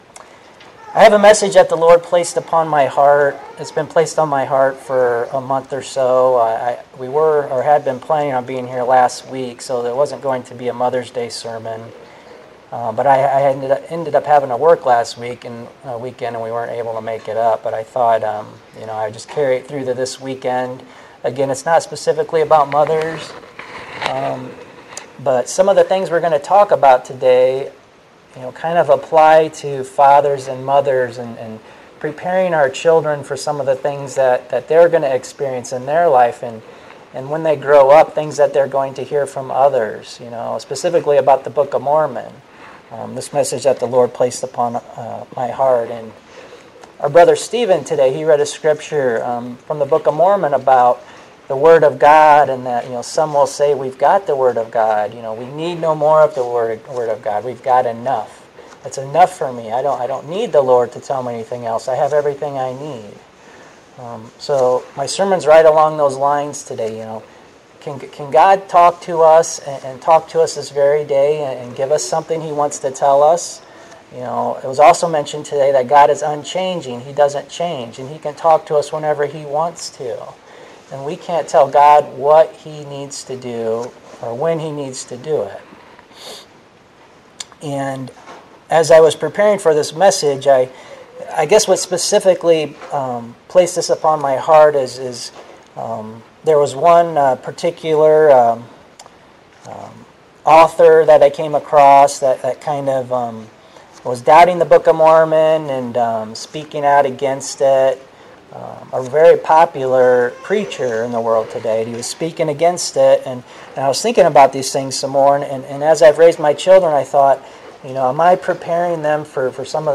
5/8/2016 Location: Bradley Local Event